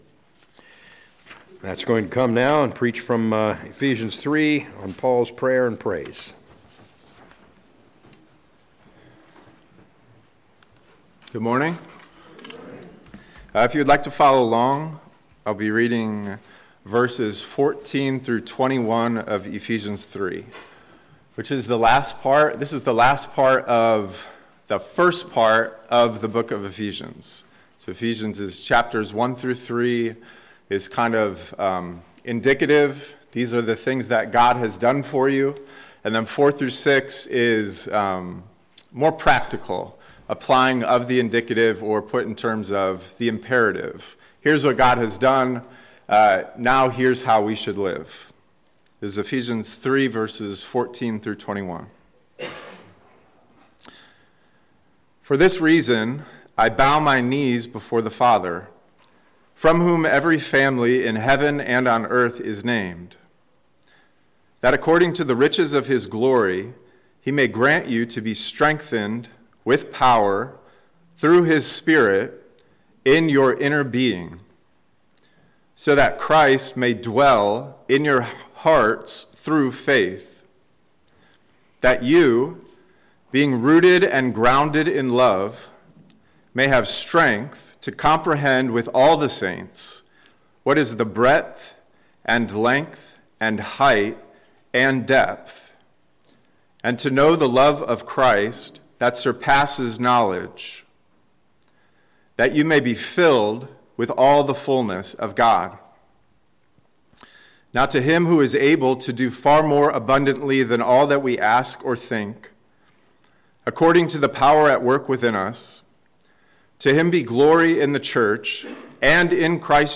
A message from the series "Ephesians 2025/2026."